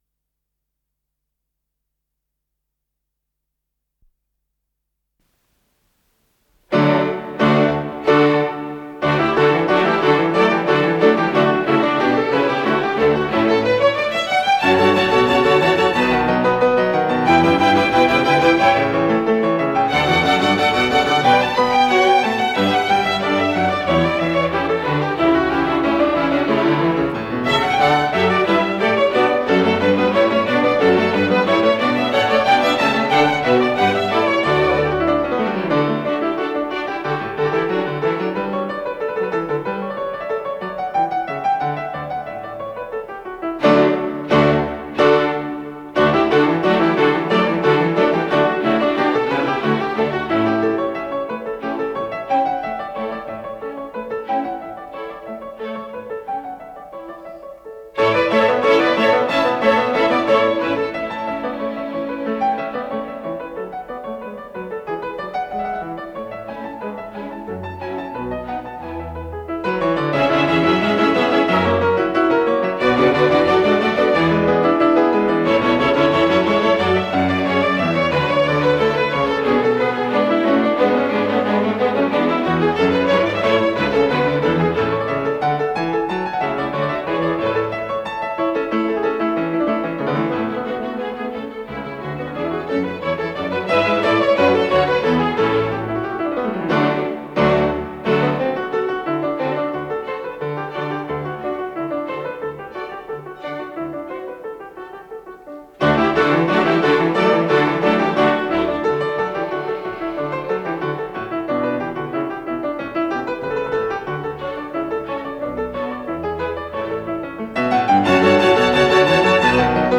Аллегро
ИсполнителиАндрей Гаврилов - фортепиано